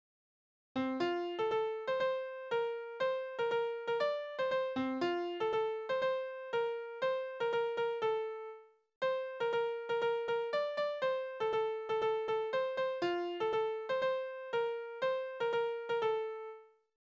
Tonart: F-Dur
Taktart: 2/4
Tonumfang: große None